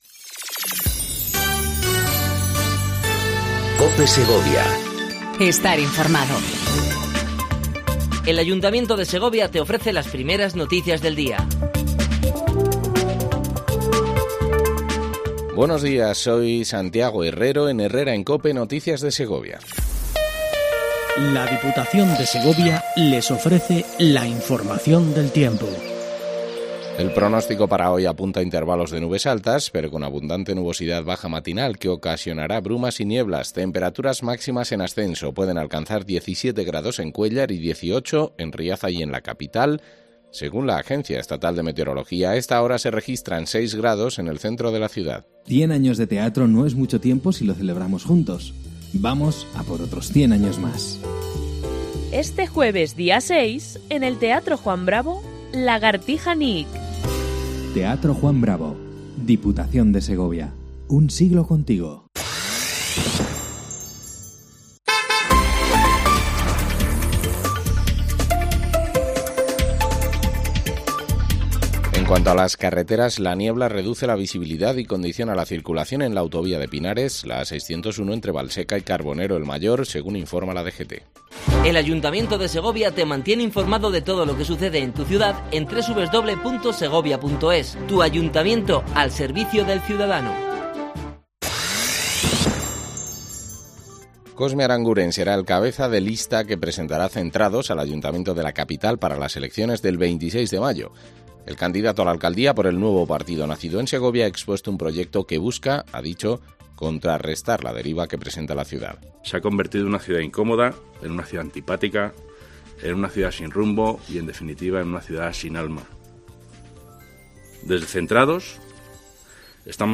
AUDIO: Primer informativo local en cope segovia